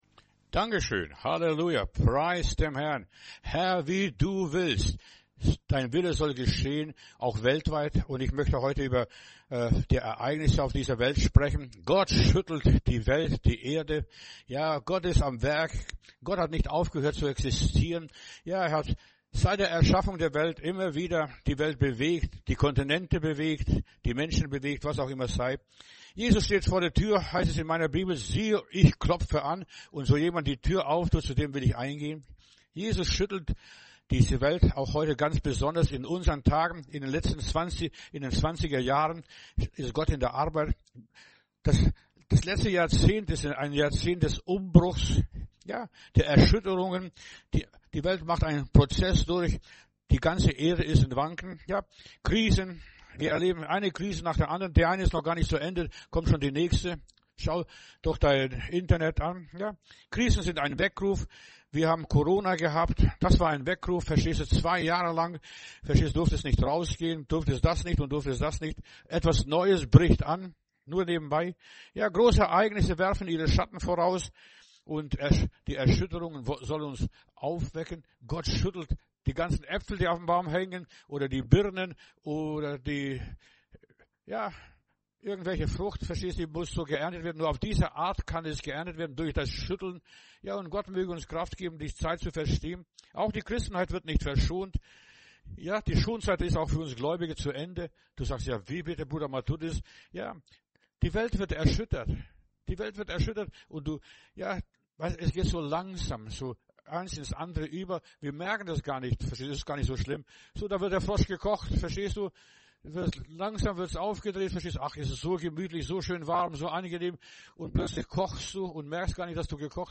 Predigt herunterladen: Audio 2026-04-19 Gott schüttelt die Welt Video Gott schüttelt die Welt